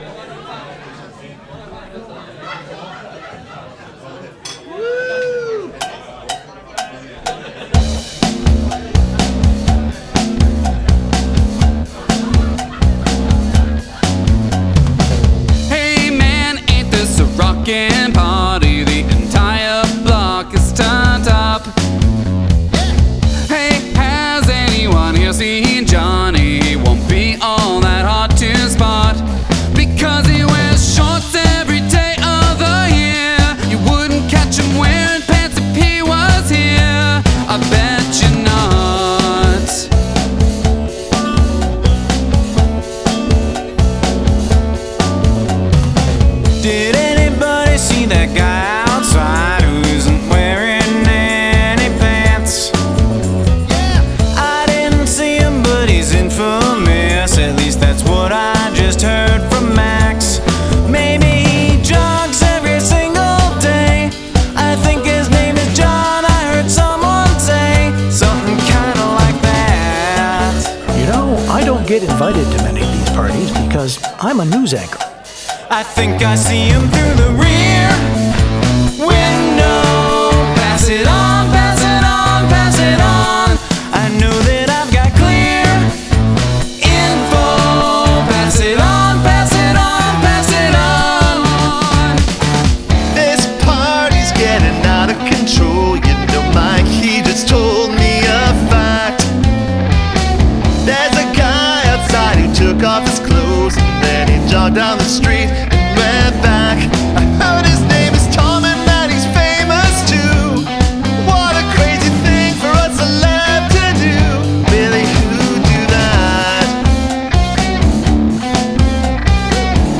Good hook in the chorus.